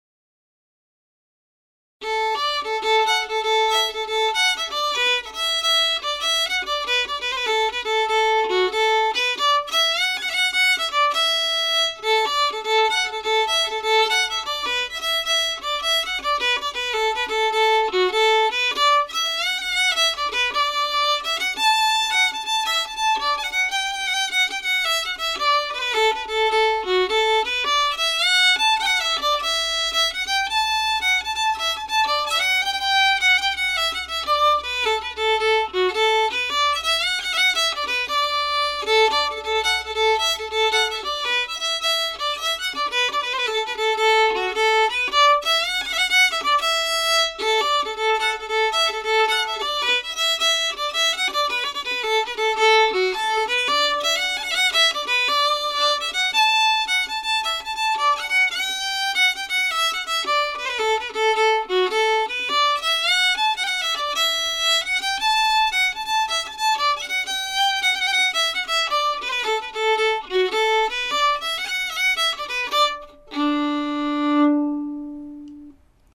A favorite Irish jig with Sligo-style ornaments.